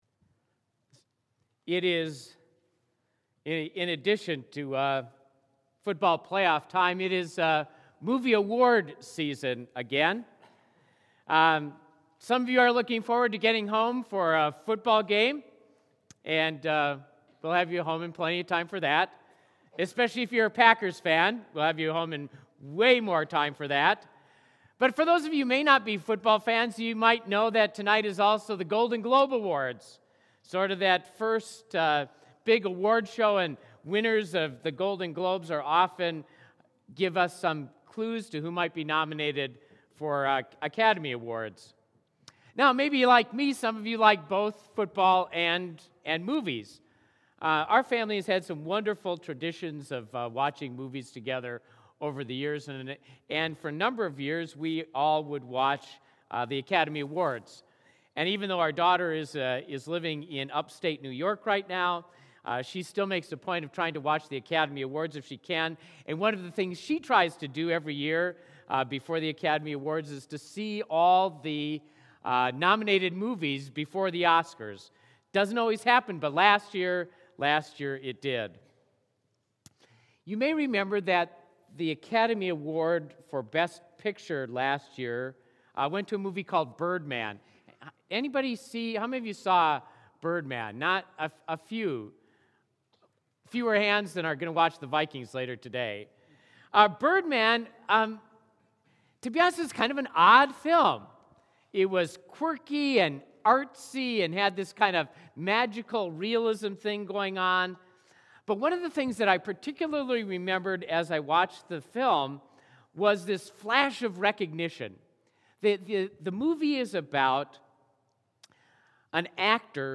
Sermon preached January 10, 2016